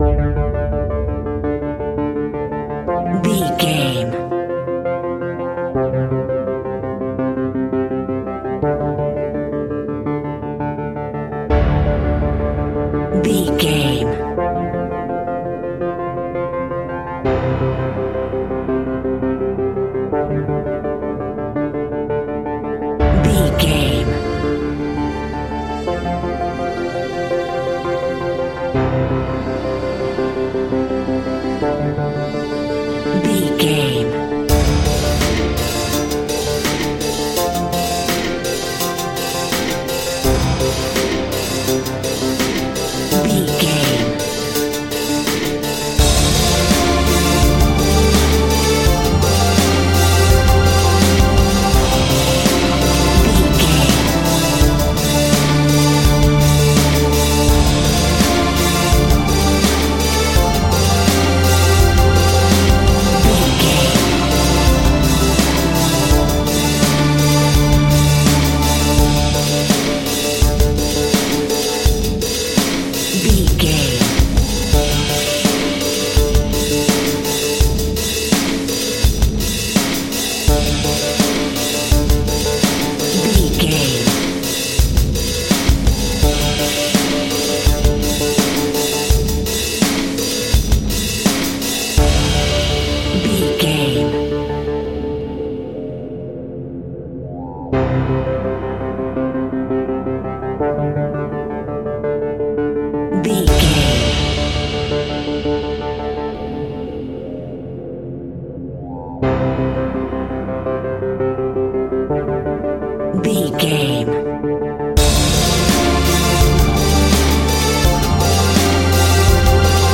Powerful Industrial Music Cue.
Aeolian/Minor
ominous
dark
haunting
strings
synthesiser
drums
piano
pads